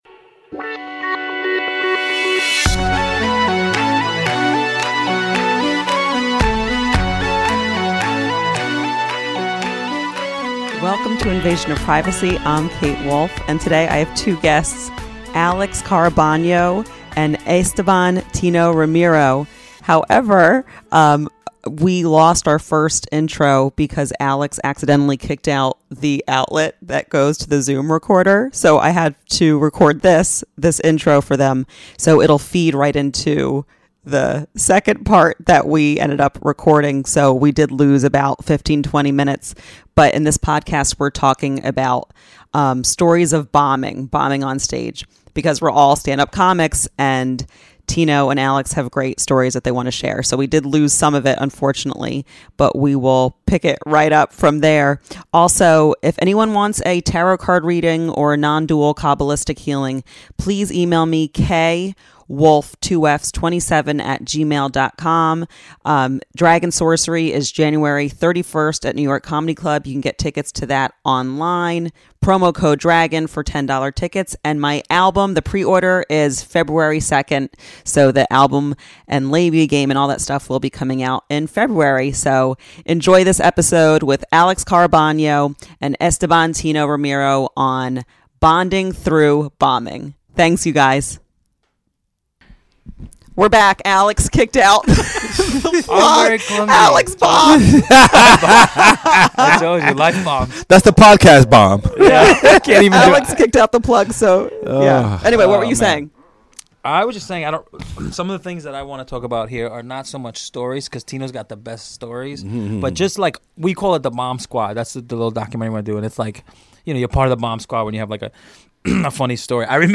and towards the end both guys get musical and show off their reggaeton skills.